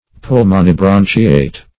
Search Result for " pulmonibranchiate" : The Collaborative International Dictionary of English v.0.48: Pulmonibranchiate \Pul`mo*ni*bran"chi*ate\, a. & n. (Zool.) Same as Pulmonate .